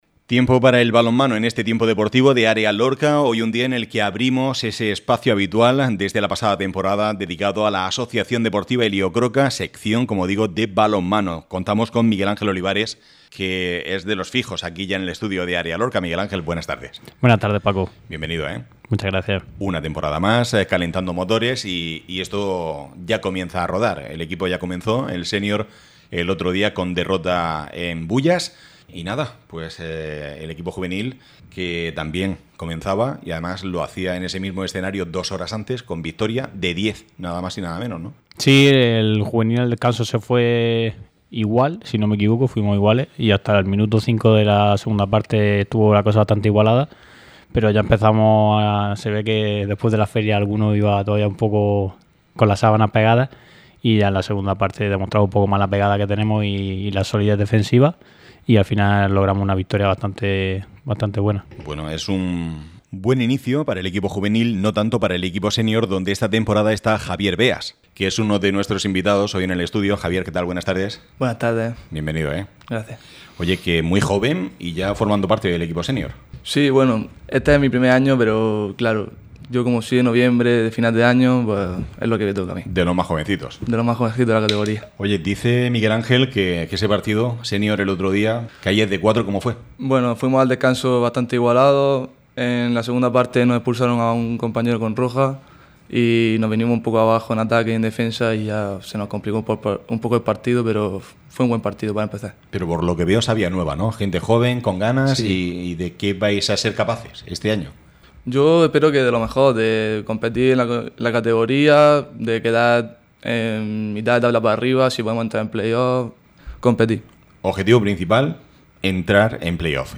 ÁREA LORCA RADIO. Deportes.